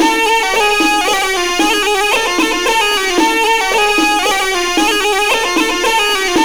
DRUM+PIPE2-L.wav